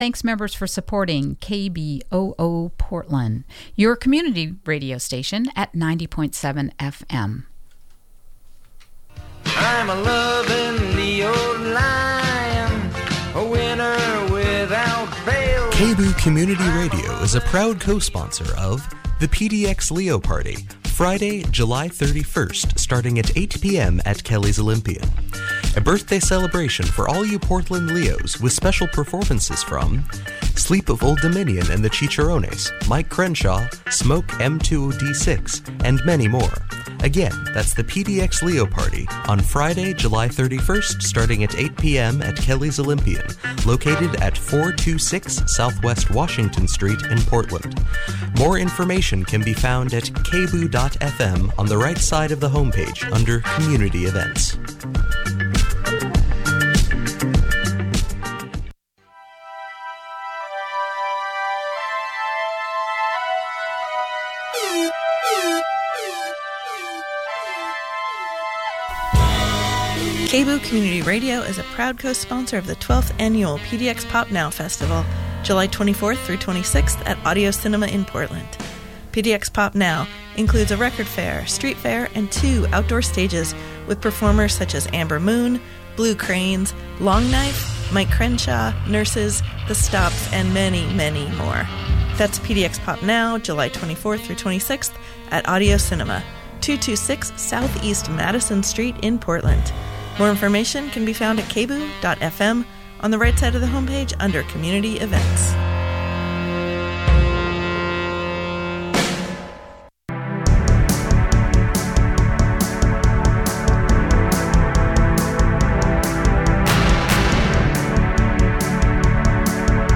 Her interview was recorded live on location on 4/23/15.